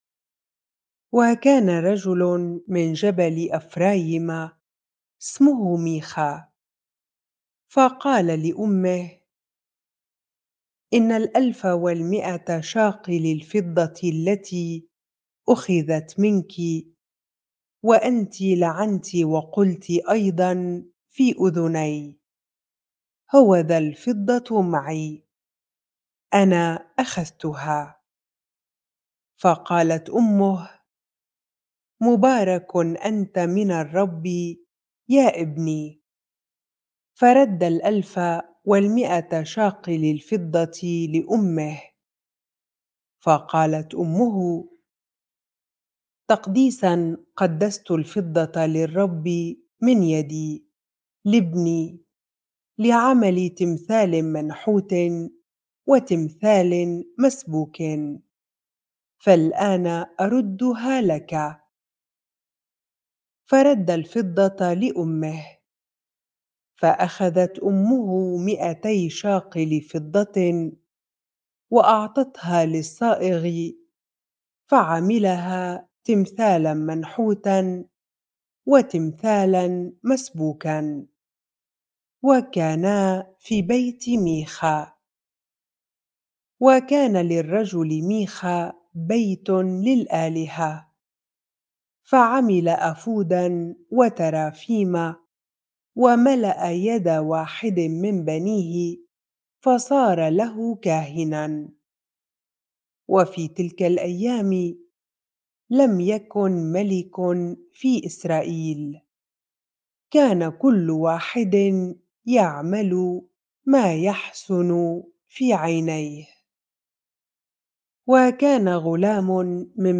bible-reading-Judges 17 ar